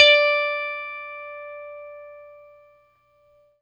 FENDRPLUCKAO.wav